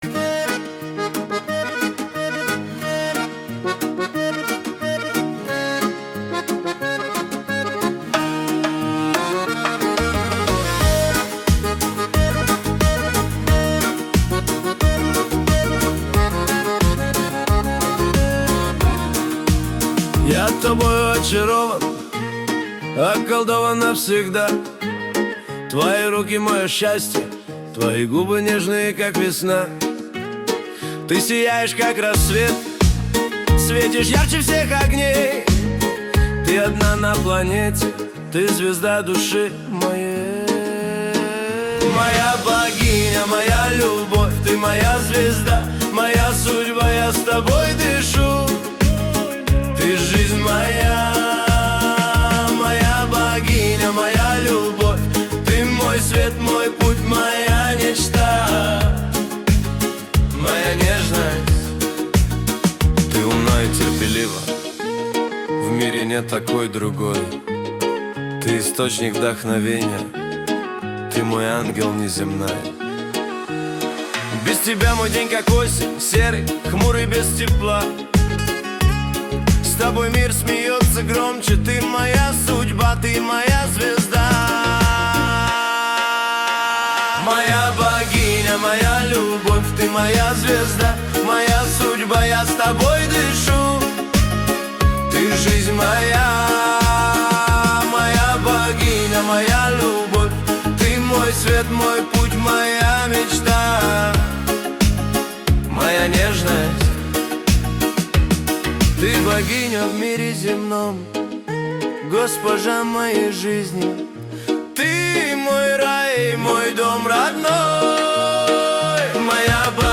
Лирическое признание в любви.